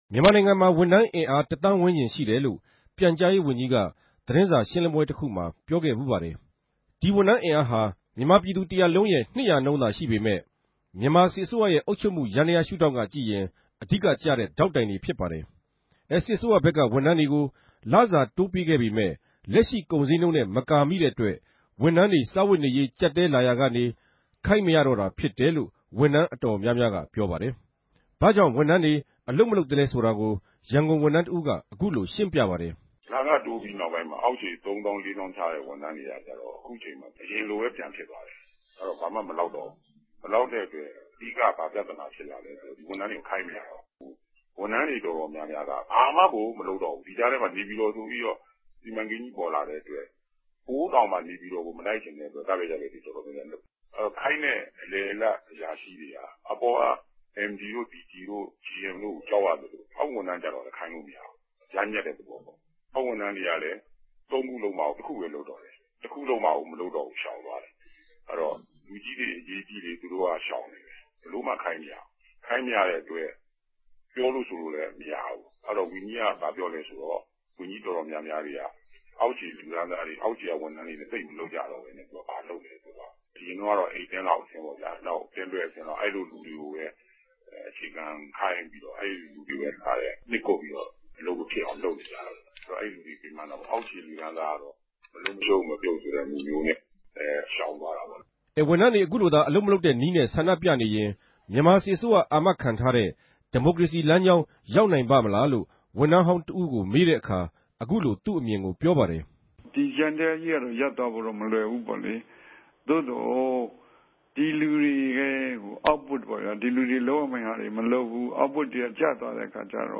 ဗန်ကောက် RFA႟ုံးခြဲကနေ သုံးသပ်တင်ူပထားပၝတယ်။